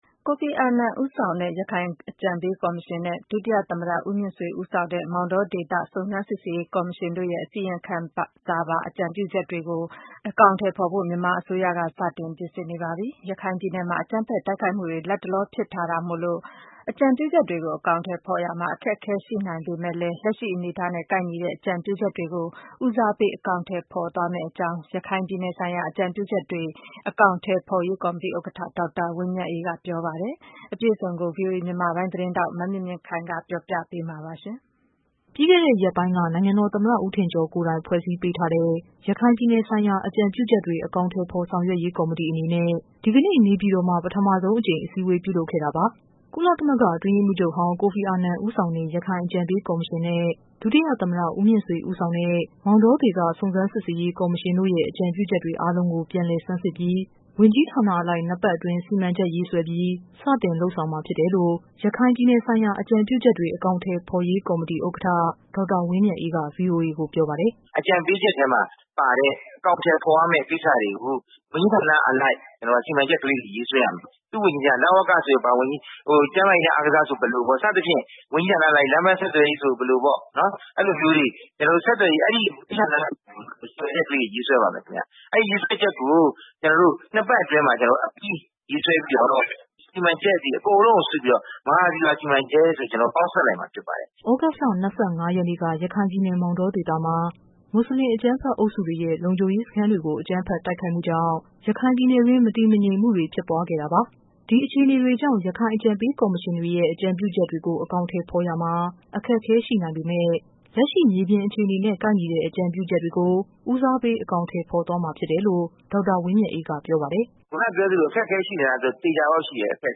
ကုလအတွင်းရေးမှူးချုပ်ဟောင်း ကိုဖီအာနန် ဦးဆောင်တဲ့ ရခိုင်အကြံပေးကော်မရှင်နဲ့ ဒုသမ္မတ ဦးမြင့် ဆွေ ဦးဆောင်တဲ့ မောင်တော ဒေသ စုံစမ်းစစ်ဆေးရေး ကော်မရှင်တို့ရဲ့ အကြံပေးချက်အားလုံးကို ပြန် လည်ဆန်းစစ်ပြီး ဝန်ကြီးဌာနအလိုက် ၂ပတ် အတွင်း စီမံချက်ရေးဆွဲပြီး စတင်လုပ်ဆောင်မှာ ဖြစ်တယ် လို့ ရခိုင်ပြည်နယ် ဆိုင်ရာ အကြံပြုချက်တွေ အကောင်အထည်ဖော်ဆောင်ရွက်ရေး ကော်မတီ ဥက္ကဋ္ဌ ဒေါက်တာ ဝင်းမြတ်အေးက ဗွီအိုအေကို ပြောပါတယ်။